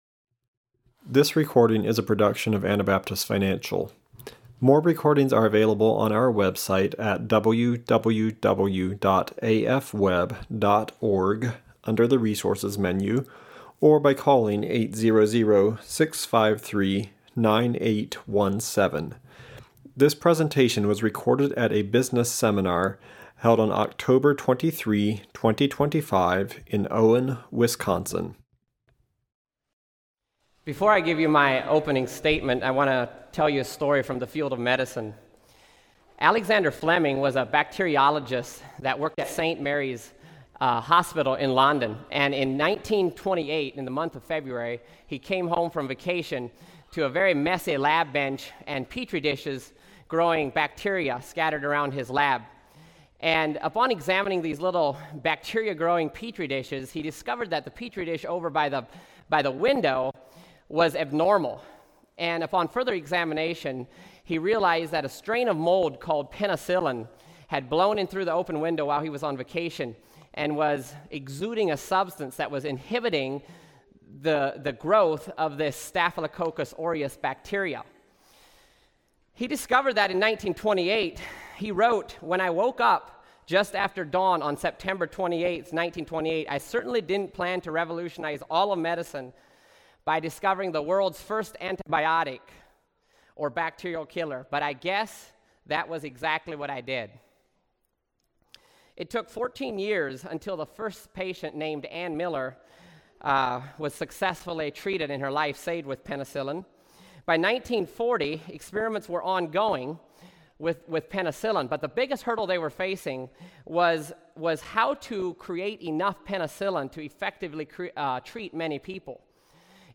Wisconsin Business Seminar 2025